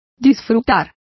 Complete with pronunciation of the translation of enjoying.